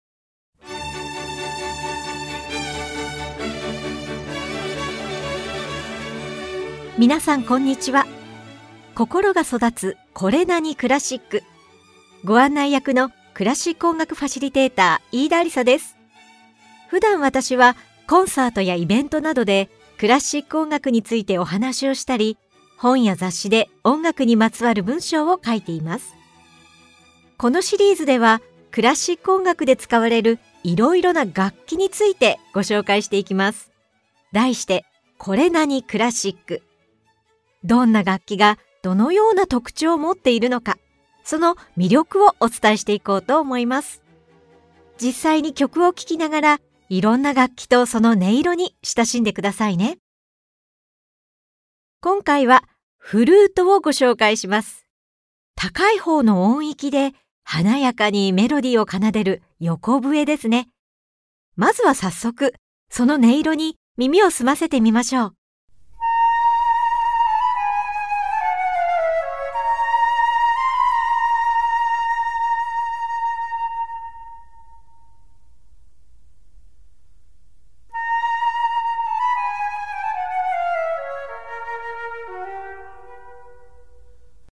[オーディオブック] 心が育つ これなに？クラシック 楽器大全 Vol.5 〜フルート〜
Vol.5では、高いほうの音域で華やかにメロディーを奏でる管楽器「フルート」に注目！ 王様にフルートを教えていた作曲家クヴァンツの曲、モーツァルトが作った「フルートとハープのための協奏曲」、フランスの作曲家プーランクが作った「フルートソナタ」などを紹介しながら、フルートのさまざまな音色とその魅力を紹介します！